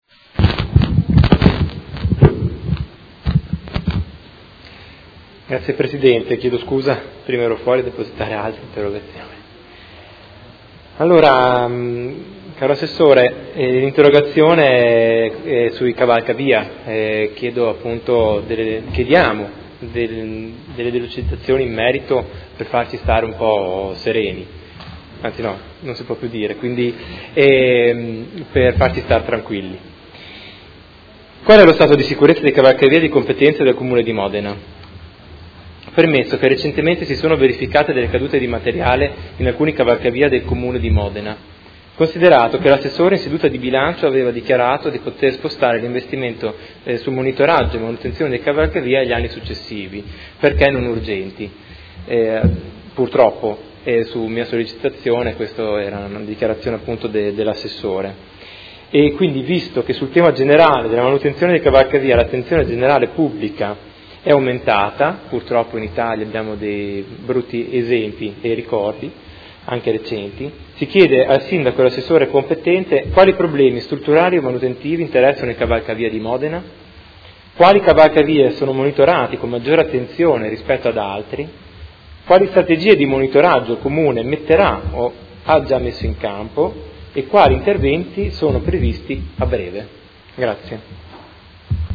Seduta del 18/05/2017. Interrogazione dei Consiglieri Chincarini (Per Me Modena) e Arletti (PD) avente per oggetto: Qual è lo stato di sicurezza dei cavalcavia di competenza del Comune di Modena?